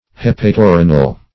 Search Result for " hepatorenal" : The Collaborative International Dictionary of English v.0.48: Hepatorenal \Hep`a*to*re"nal\, a. [Hepatic + renal.]